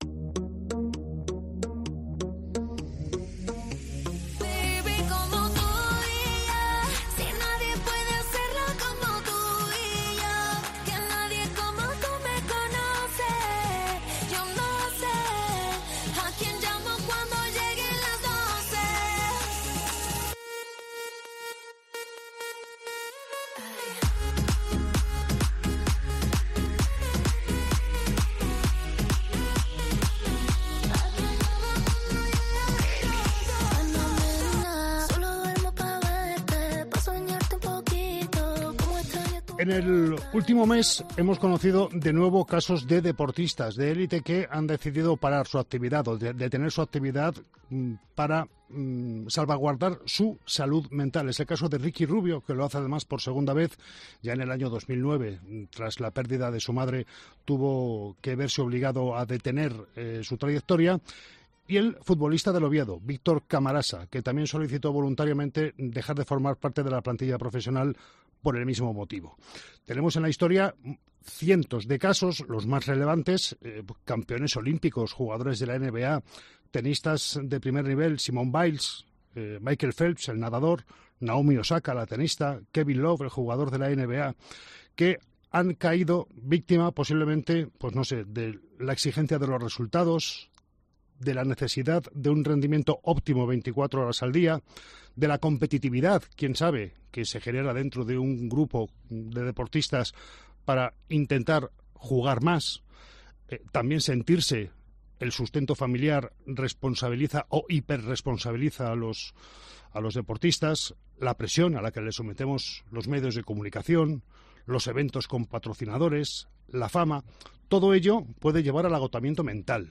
En una charla en Deportes COPE Valladolid